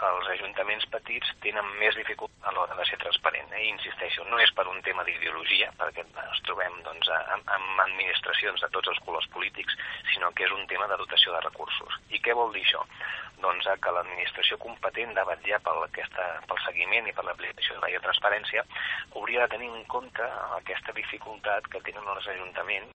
ha detallat en una entrevista al Bon dia alguns dels aspectes en què la pàgina web de l’Ajuntament de Calella ha de millorar per complir amb els indicadors de transparència extrets de la nova normativa.